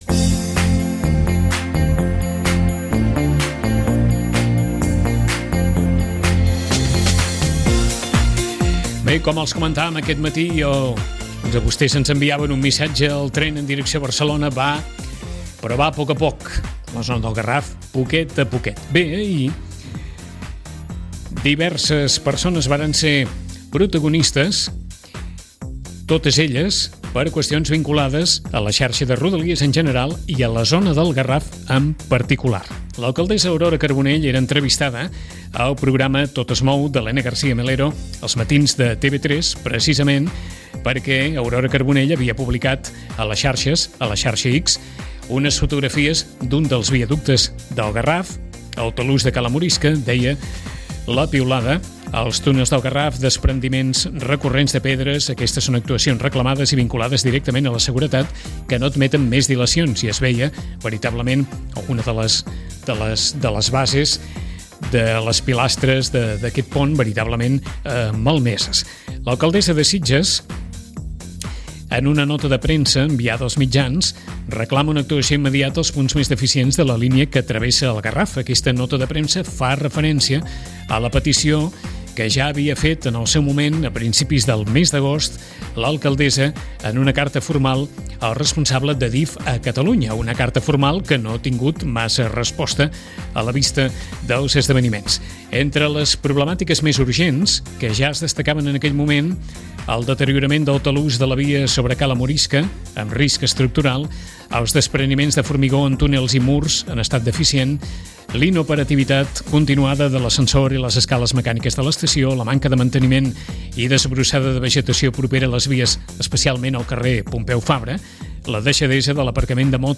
Hem volgut aplegar els aspectes més destacats d’aquestes declaracions. Al programa ‘Tot es mou’ de TV3 l’alcaldessa Aurora Carbonell recordava com fa mesos l’Ajuntament envià una carta als responsables d’ADIF assenyalant-los un seguit de mancances en la infraestuctura ferroviària, i la tèbia resposta que va tenir.
A ‘La hora de la 1’ el ministre Oscar Puente es referia també a les mateixes obres, i al ‘Versió RAC1 el secretari d’estat de transports i mobilitat sostenible, Jose Antonio Santano, afirmà que l’actuació als túnels de Garraf començarà ‘cuanto antes’.